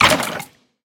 Minecraft Version Minecraft Version snapshot Latest Release | Latest Snapshot snapshot / assets / minecraft / sounds / mob / wither_skeleton / hurt3.ogg Compare With Compare With Latest Release | Latest Snapshot
hurt3.ogg